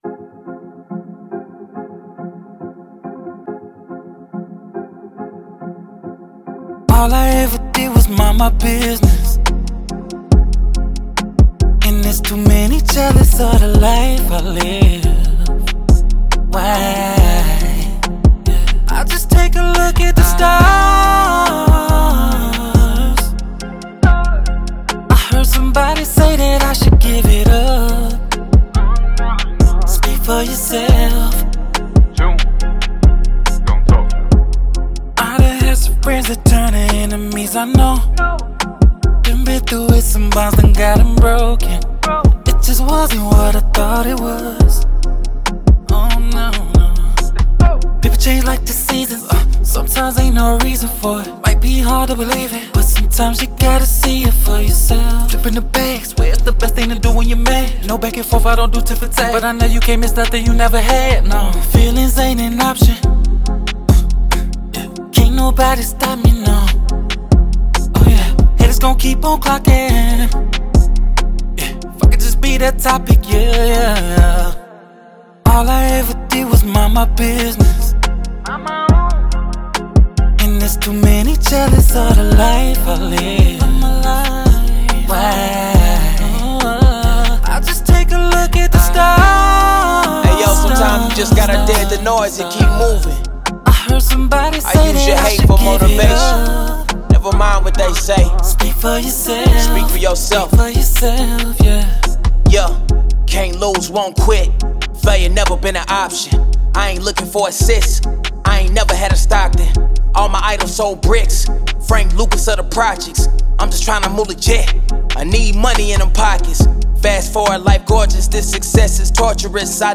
Hip Hop, R&B
F Minor